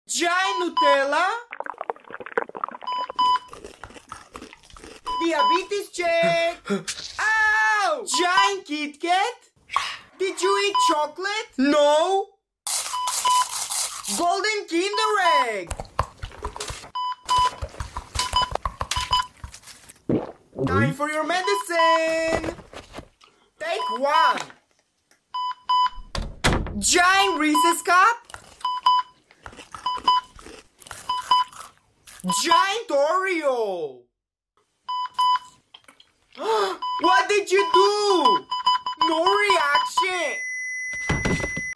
ASMR